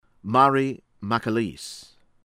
BEHR- tee a- HER-N